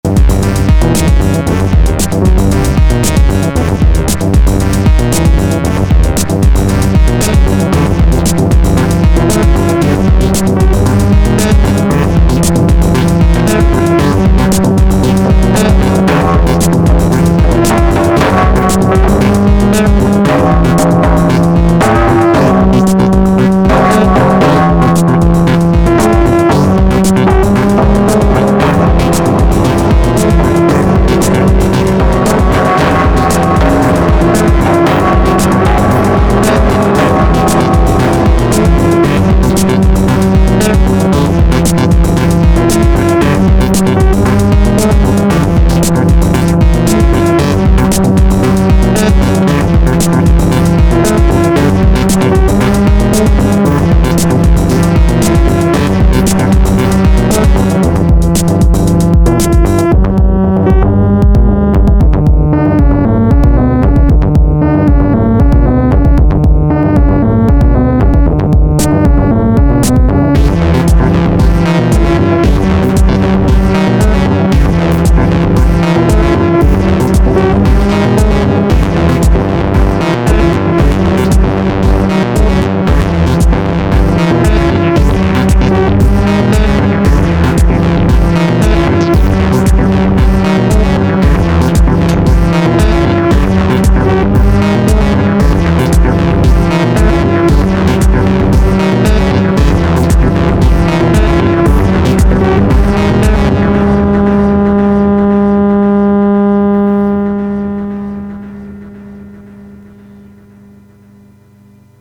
And here’s some DT techno patterns from last night